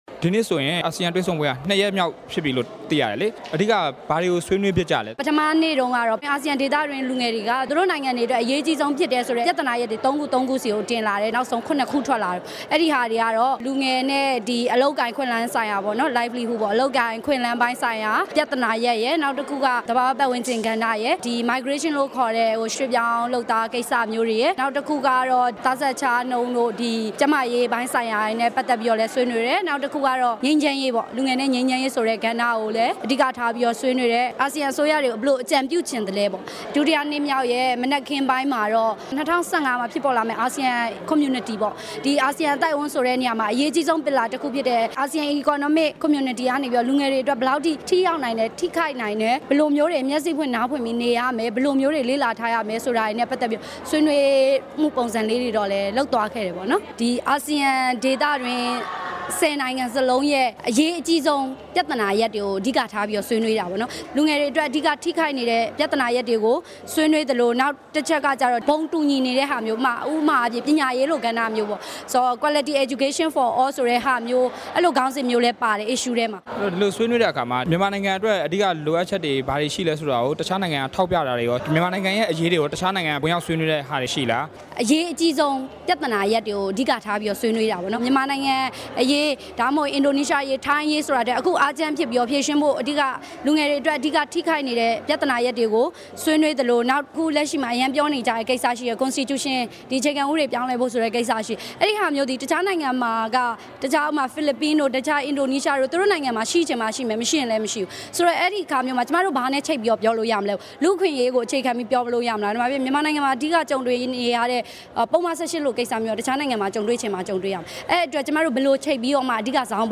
အာဆီယံလူငယ်ဖိုရမ်အကြောင်း မေးမြန်းချက်